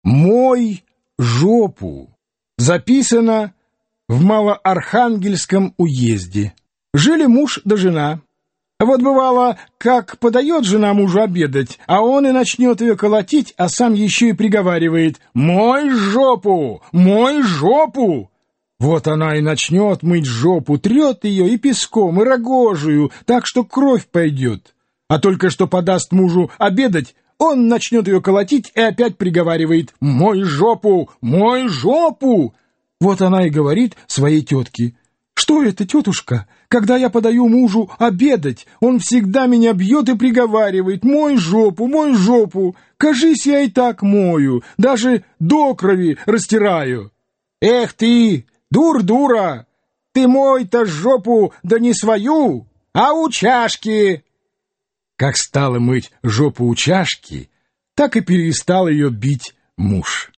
Аудиокнига Русские заветные сказки | Библиотека аудиокниг